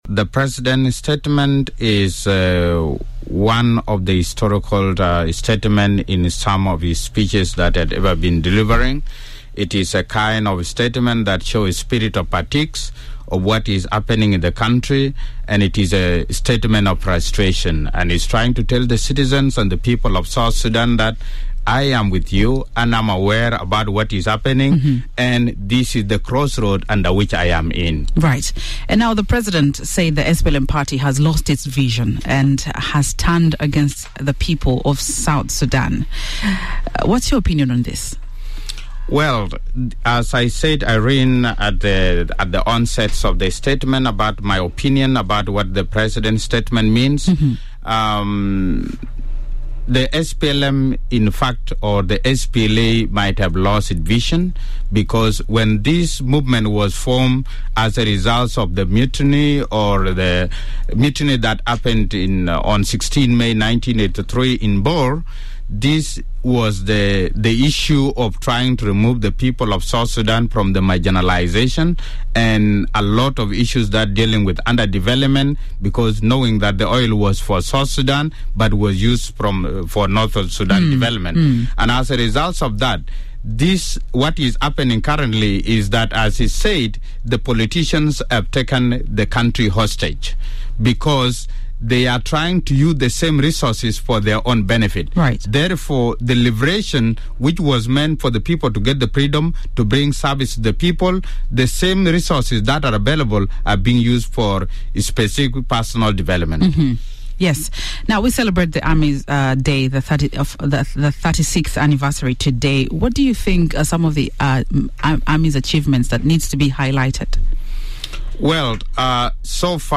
brief interview